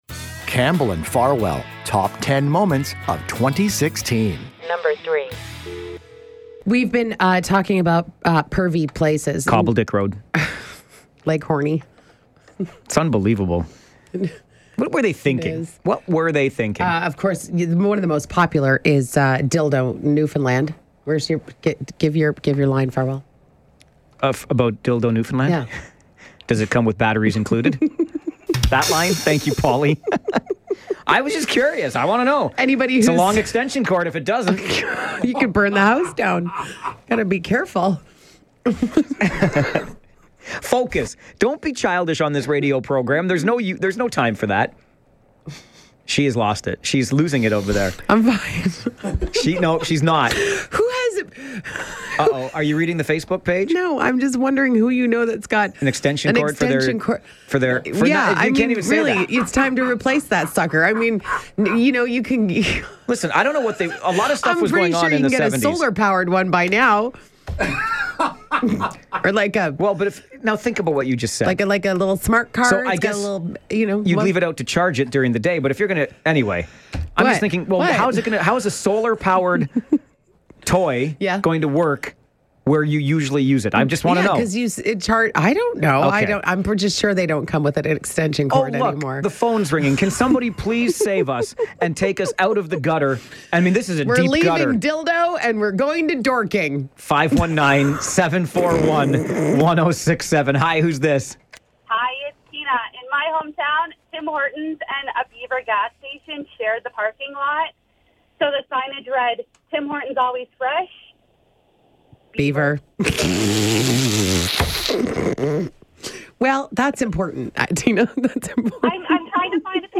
Well, this call to the show now gives us another reason.